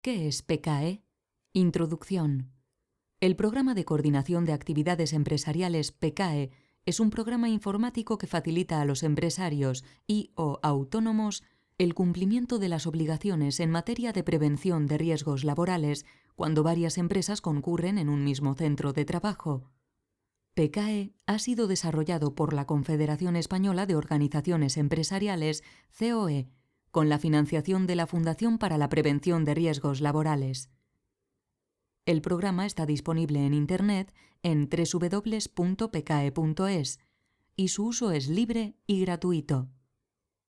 Voz media.
Sprechprobe: Industrie (Muttersprache):